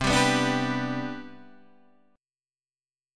C Major 7th Suspended 2nd